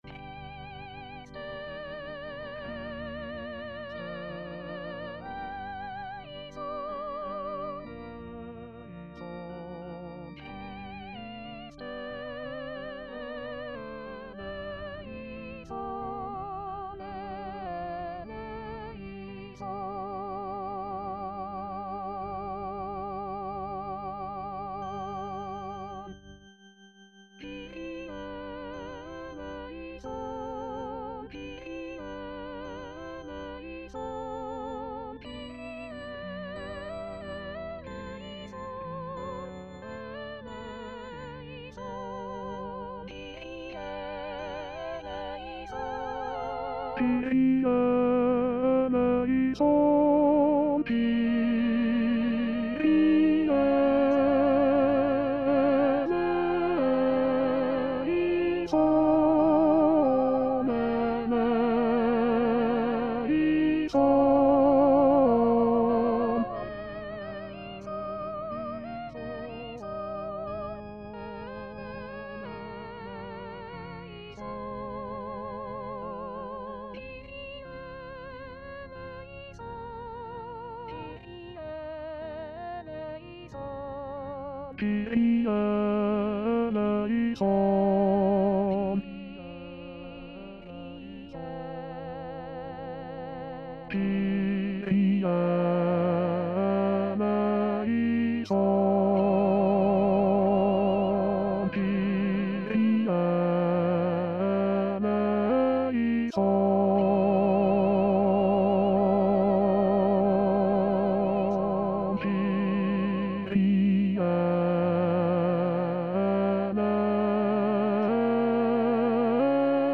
Segment E:  80-122 (avec solistes)
Chanté:     S   A